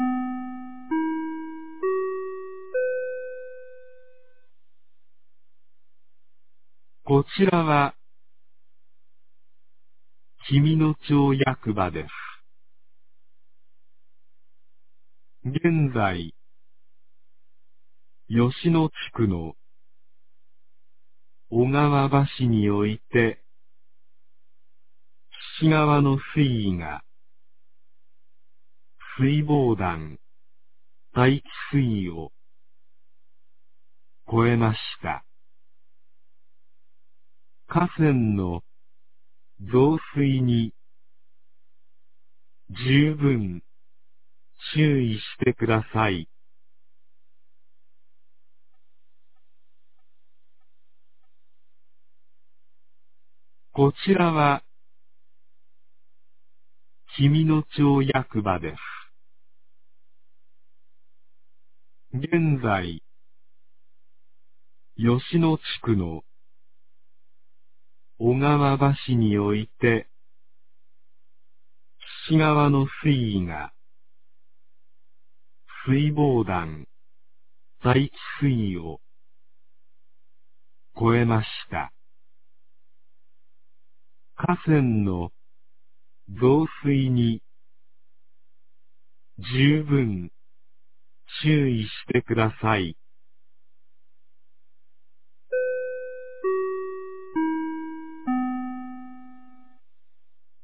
2023年06月02日 11時52分に、紀美野町より全地区へ放送がありました。
放送音声